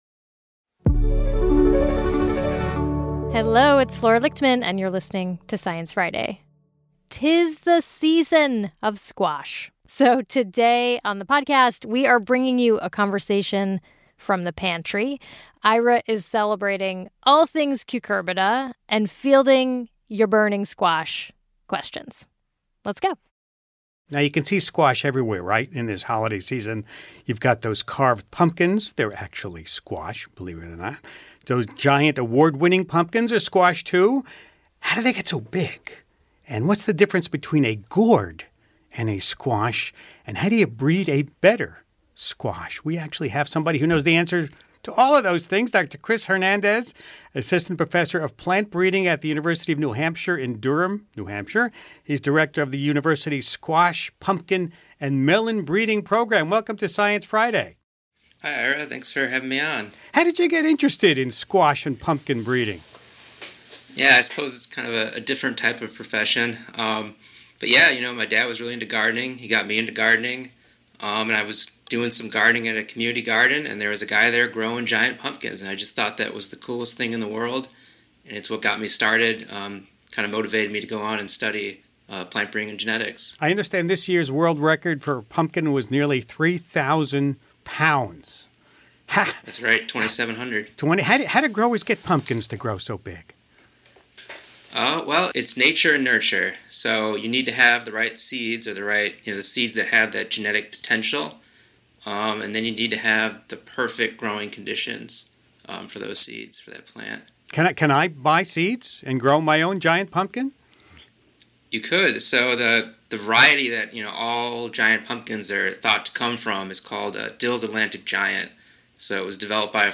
In a conversation from 2023, an expert answers listener questions about these colorful fall favorites.